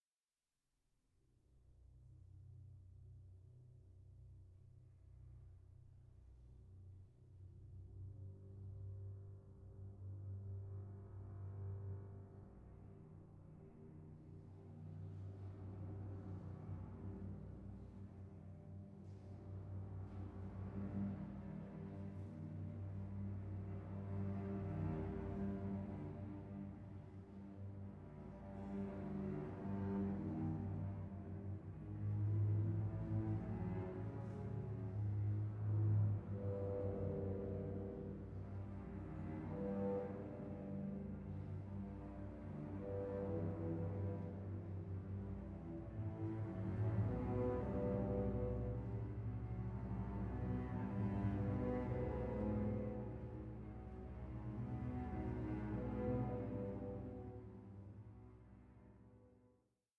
(96/24) Stereo  14,99 Select
for Solo Violin and Orchestra